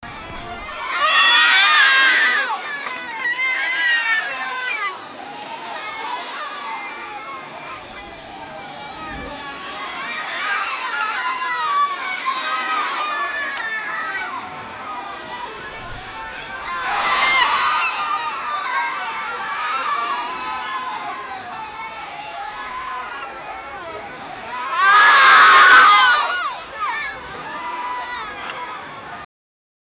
Crowd throwing a child temper tantrum.
crowd-throwing-a-child-te-r2pvr5qh.wav